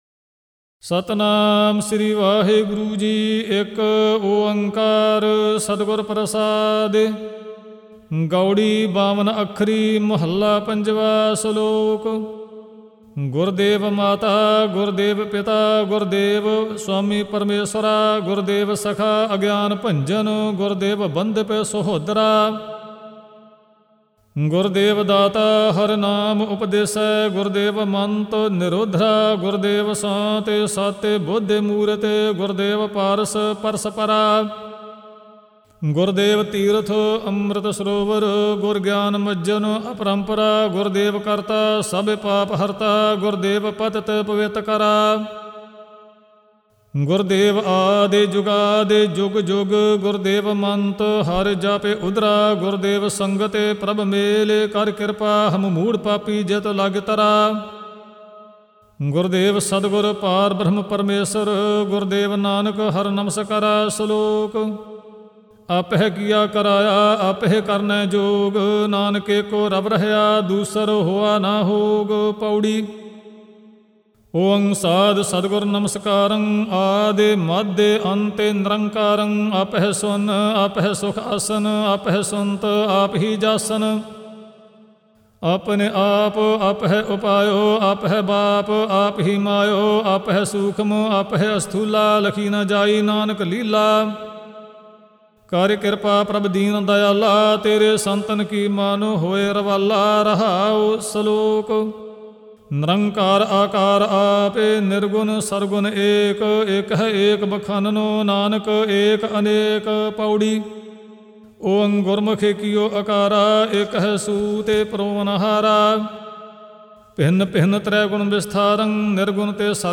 Gurbani Ucharan(Paath Sahib)
-Gurbani Ucharan Album Info